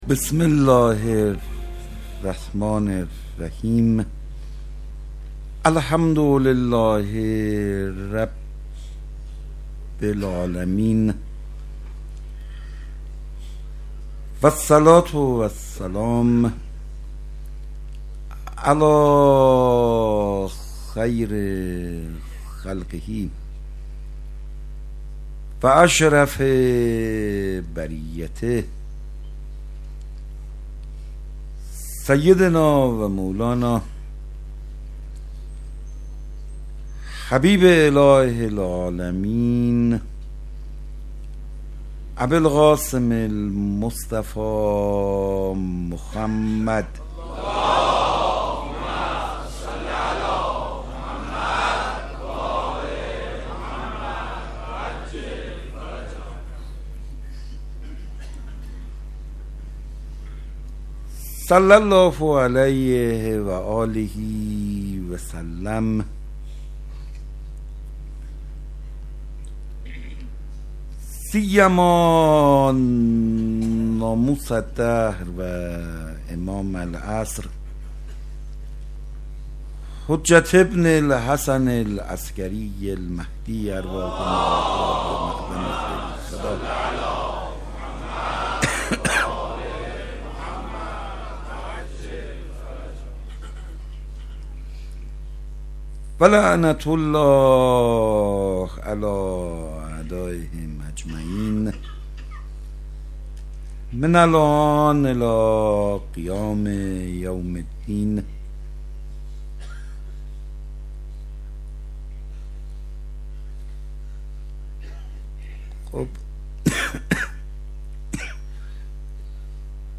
مناسبت : شب دوم محرم